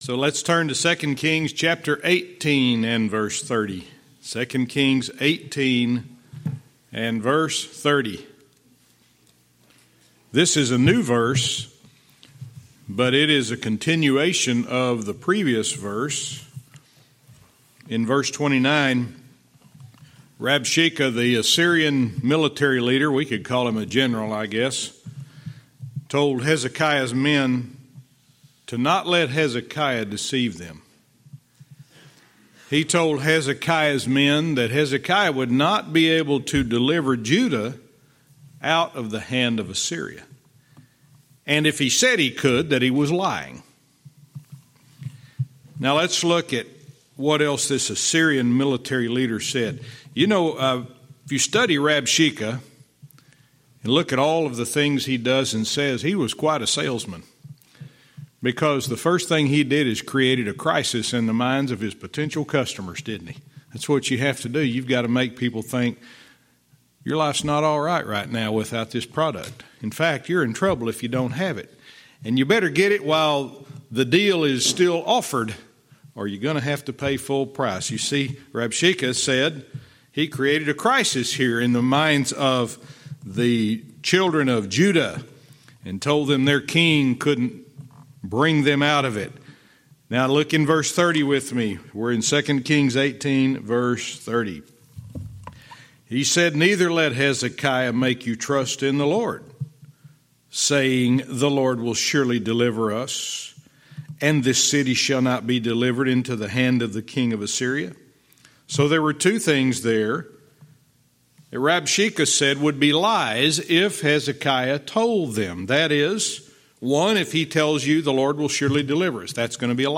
Verse by verse teaching - 2 Kings 18:30,31